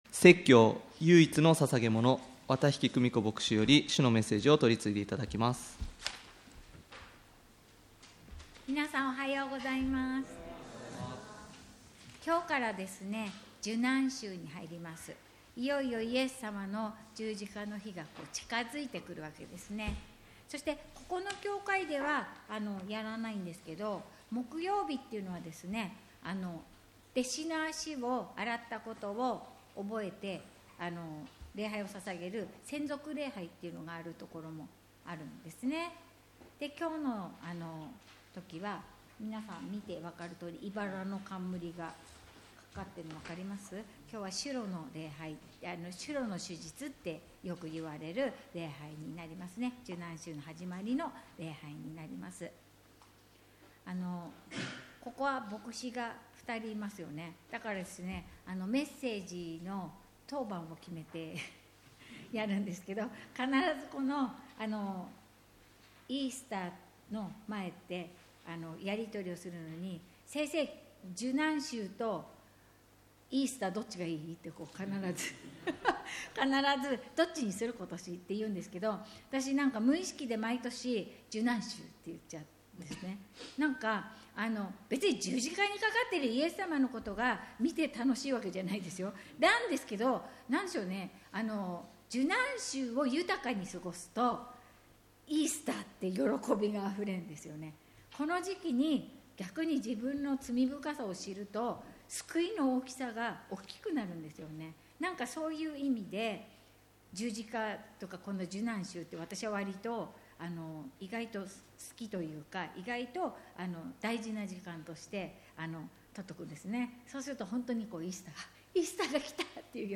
唯一の献げ物 - 日本キリスト教団 勝田教会